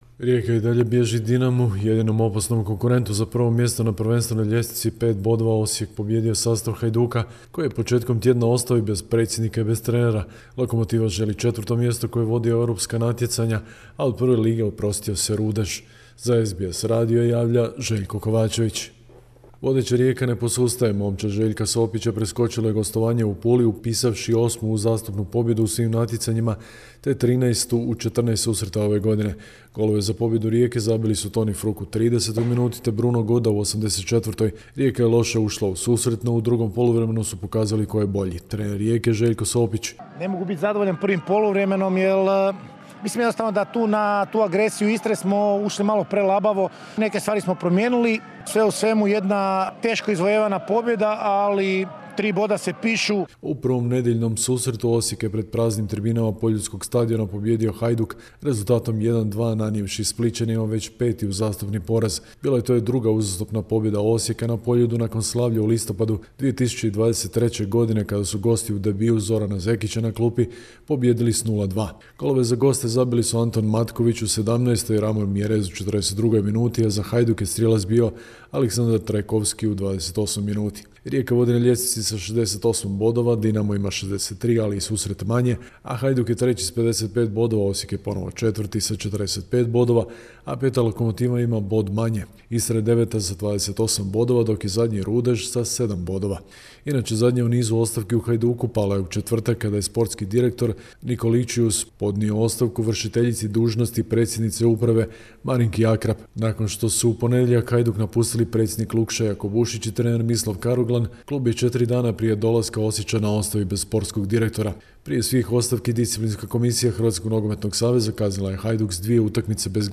Sportske vijesti iz Hrvatske, 15.4.2024.